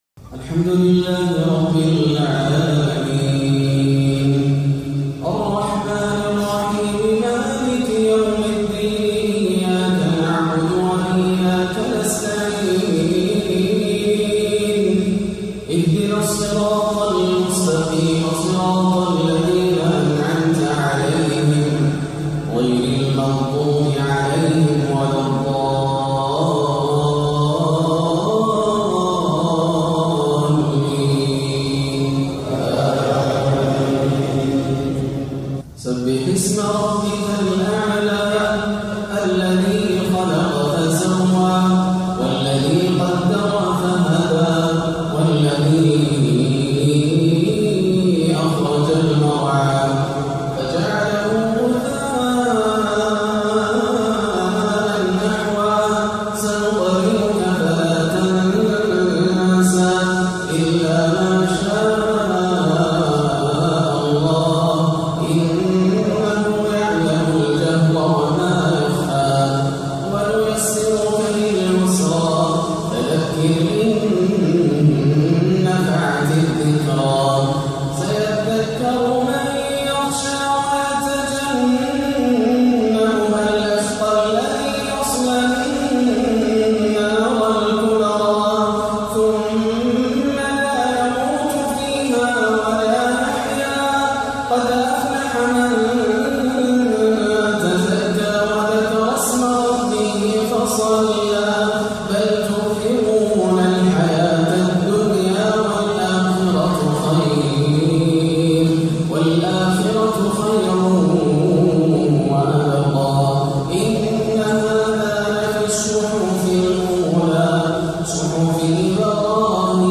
سورتي الأعلى والغاشيه بأداء عذب وخاشع - صلاة الجمعة 13-1 > عام 1438 > الفروض - تلاوات ياسر الدوسري